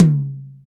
Index of /90_sSampleCDs/Roland L-CD701/KIT_Drum Kits 2/KIT_Dry Kit
TOM RLTOM10J.wav